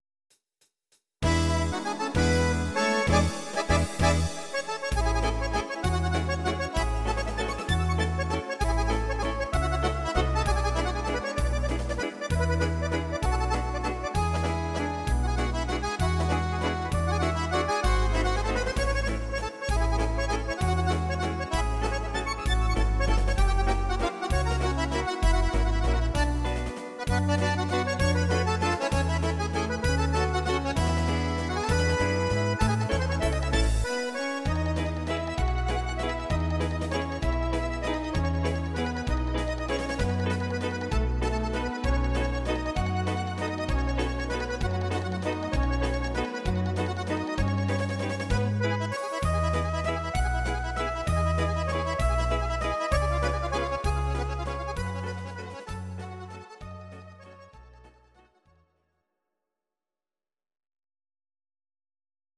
Akkordeon